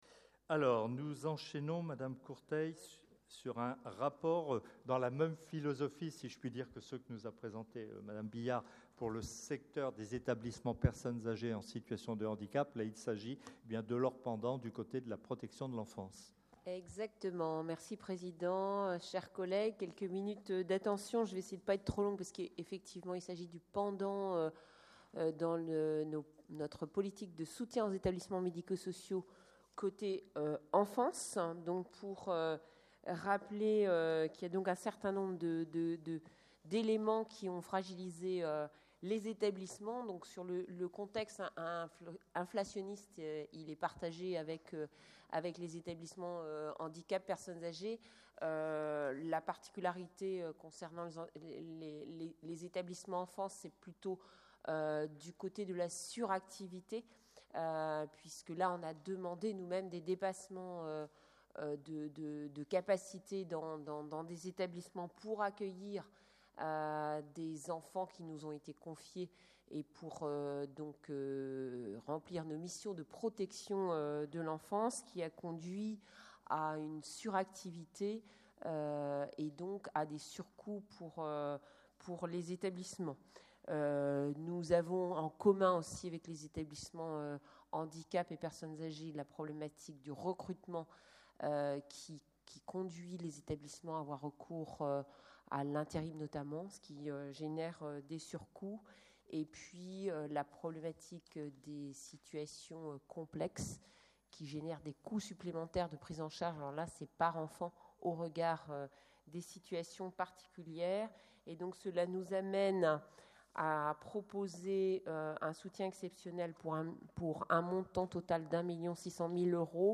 Assemblée départementale